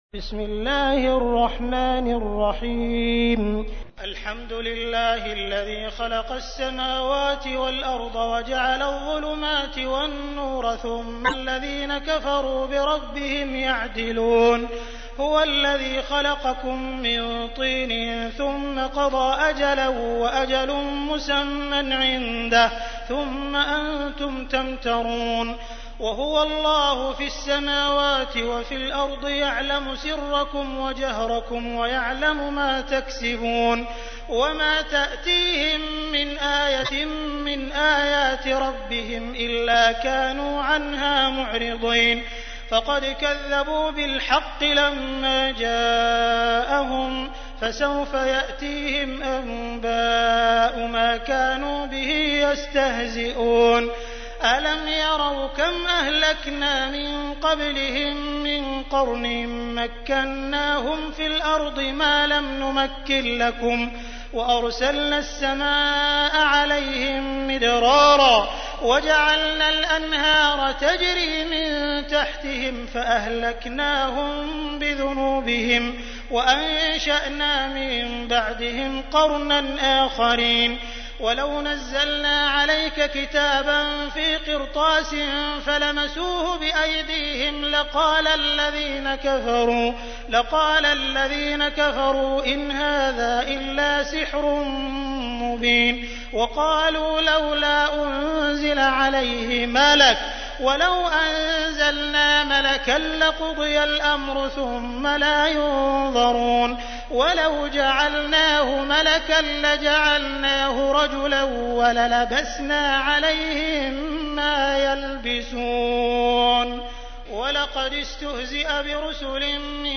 تحميل : 6. سورة الأنعام / القارئ عبد الرحمن السديس / القرآن الكريم / موقع يا حسين